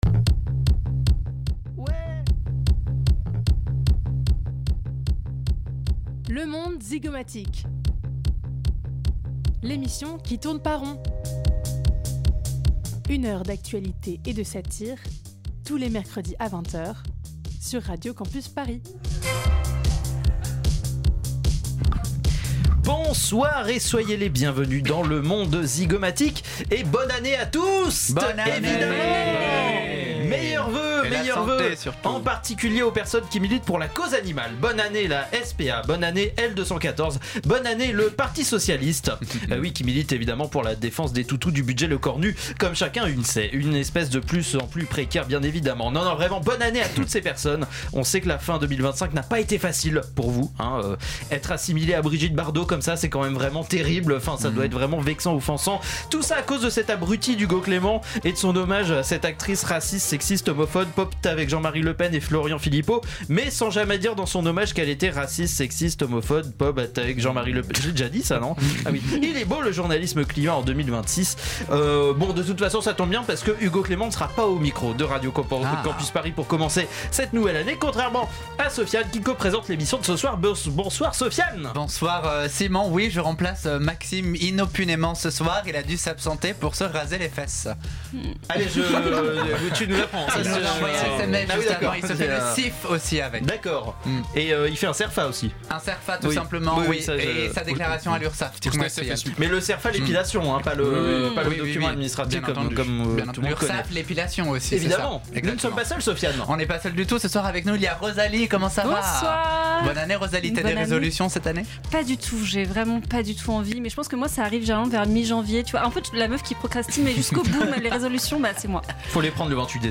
Type Magazine Société